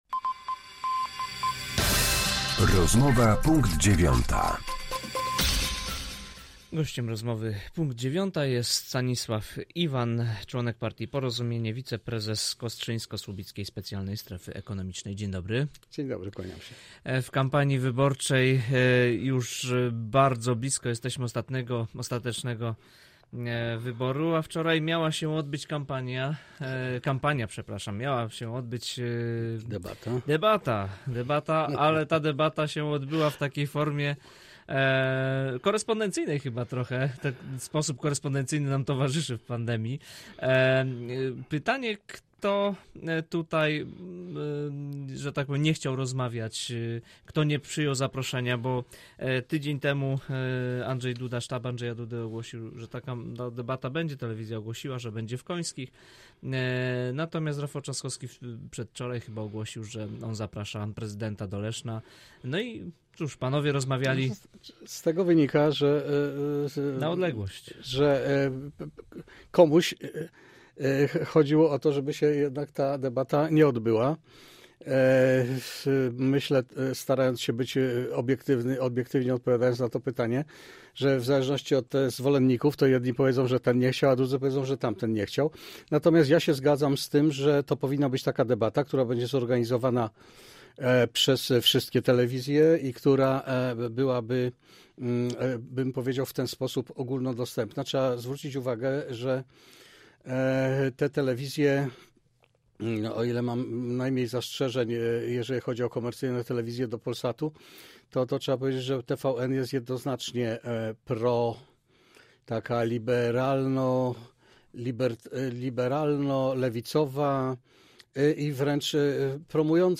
Z członkiem partii Porozumienie rozmawia